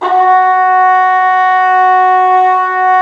Index of /90_sSampleCDs/Roland L-CDX-03 Disk 2/BRS_Cup Mute Tpt/BRS_Cup Ambient